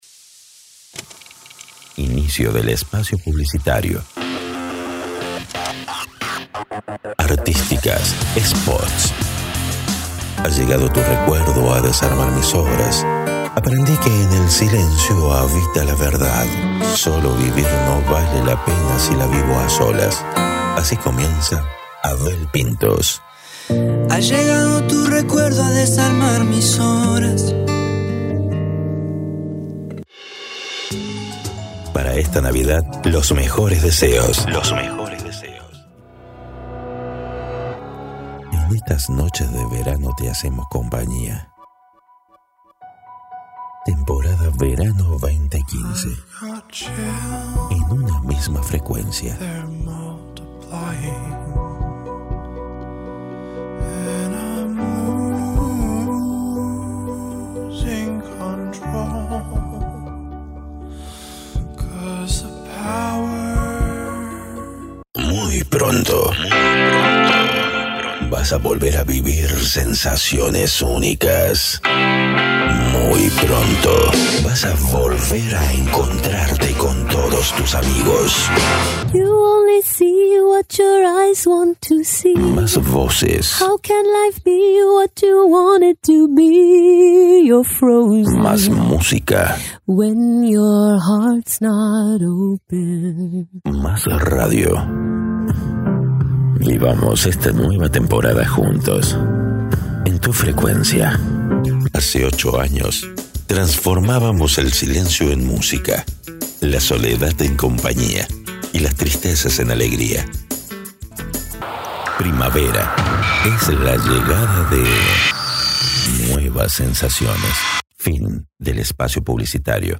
Talento con voz profunda, grave e institucional
Sprechprobe: Werbung (Muttersprache):
As voice talent, I specialize in low, institutional, formal, intimate but also dynamic, energetic and promotional tones.